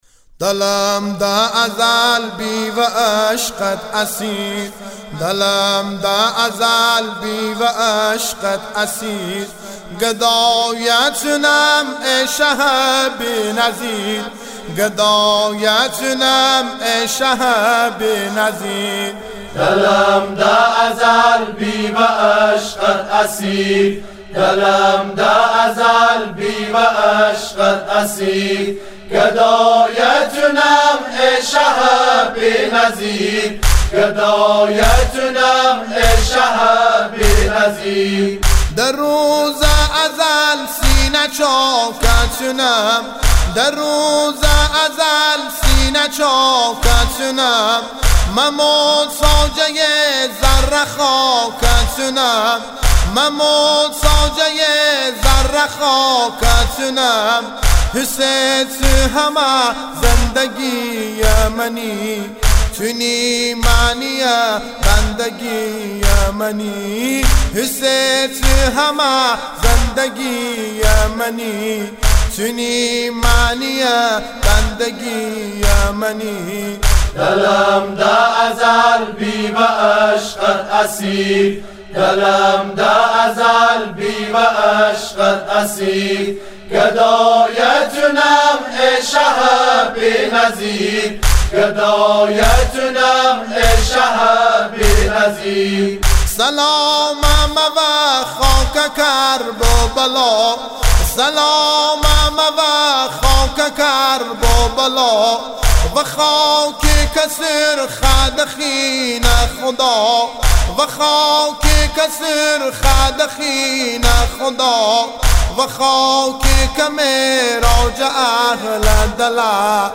نوحه و مداحی لری خرم آبادی